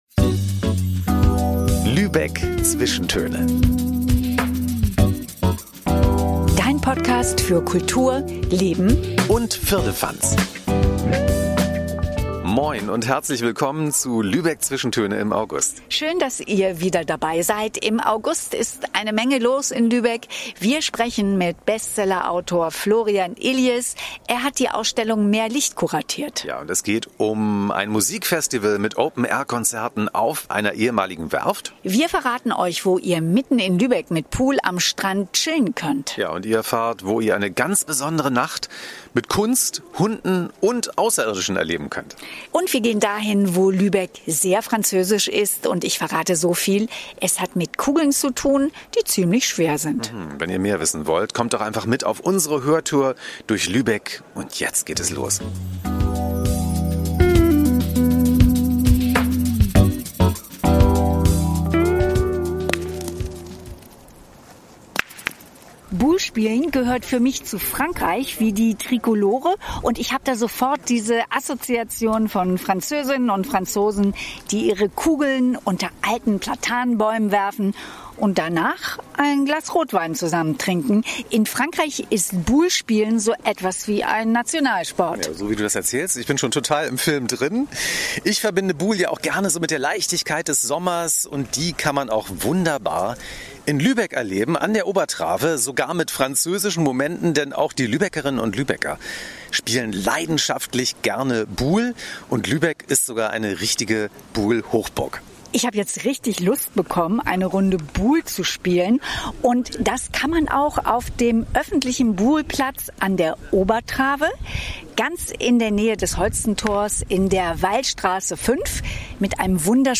Wir sprechen mit Bestseller-Autor Florian Illies, der hier die Ausstellung „Mehr Licht!“ kuratiert hat. Es geht um ein Musikfestival mit Open-Air-Konzerten auf einer ehemaligen Werft. Wir verraten dir, wo du mitten in Lübeck mit Pool am Strand chillen kannst.